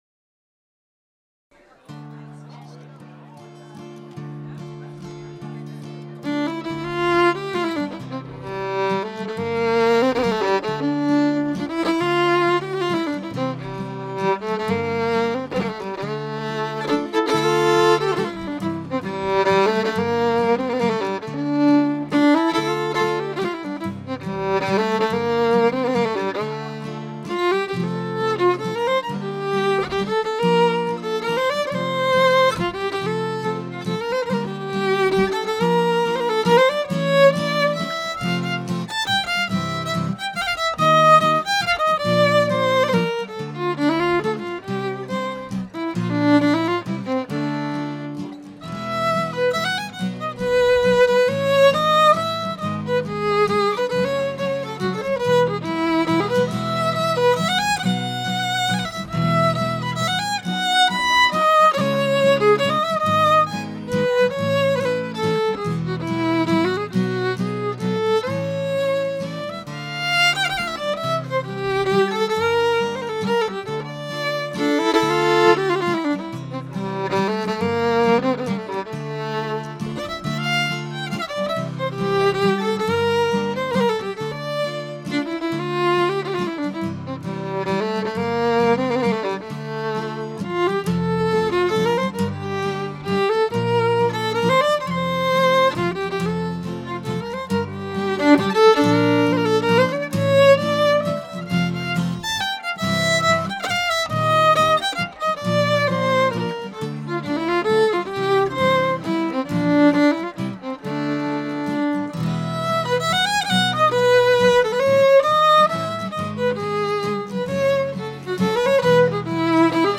It's a nice document of what the two of us sound like in a relaxed setting.
It's a nice chord workout for you guitar players :)